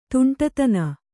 ♪ tuṇṭatana